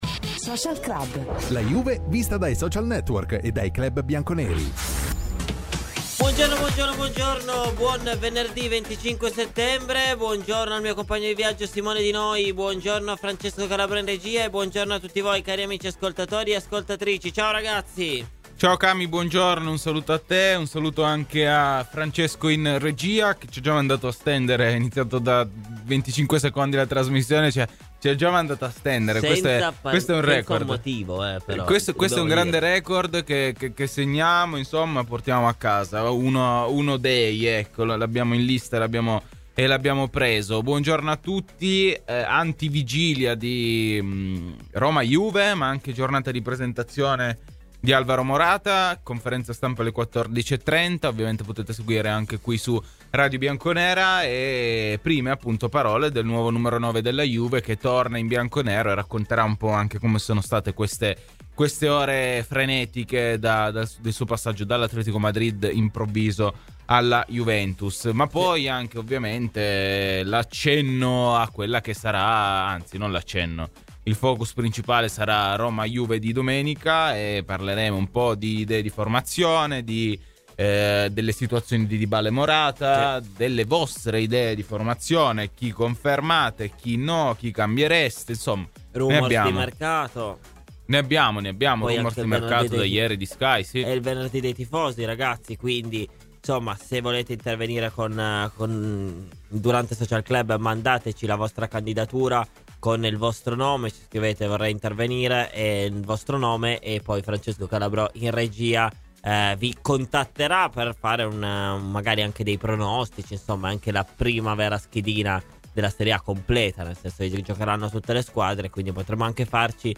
Clicca sul podcast in calce per l'intervento completo.